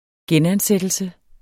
Udtale [ ˈgεn- ]